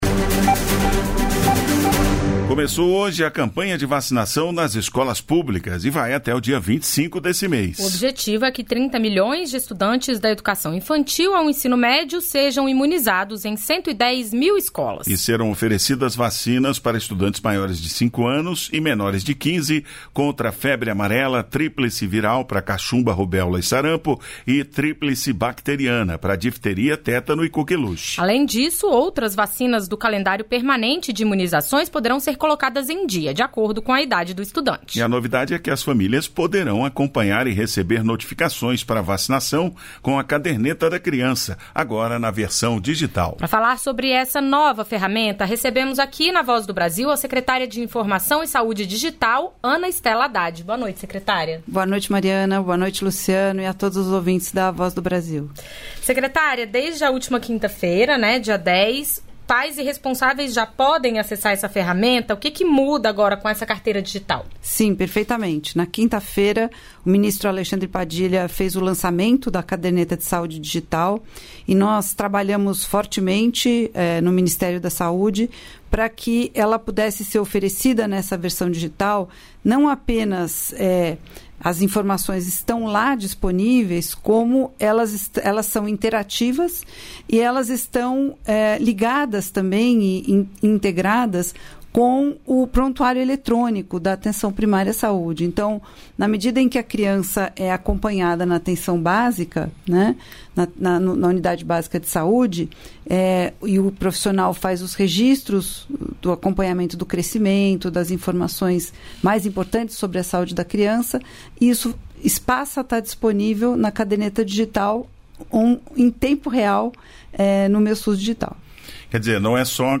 Em entrevista, o ministro falou sobre o Minha Casa Minha Vida. O programa vai começar a atender famílias de classe média, com faixa de renda entre R$ 8 mil e R$ 12 mil por mês.